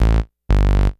Bass_01.wav